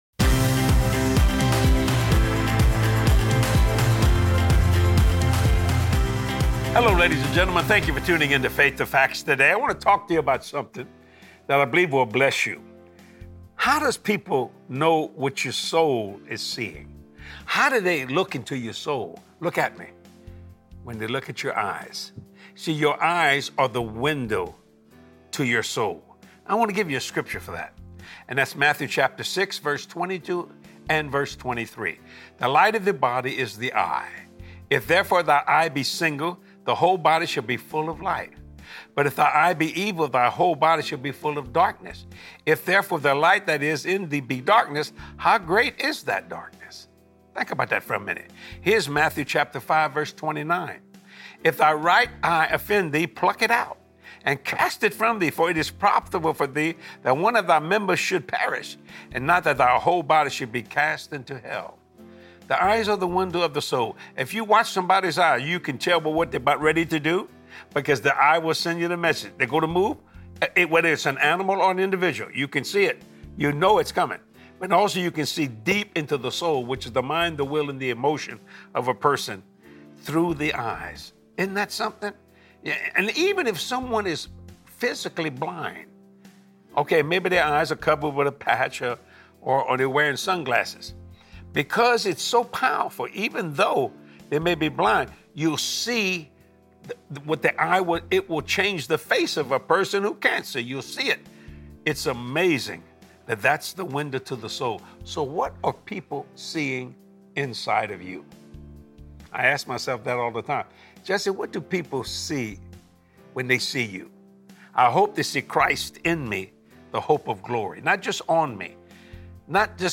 What do people SEE in you? Be inspired to walk in the ways of Christ as you watch this powerful teaching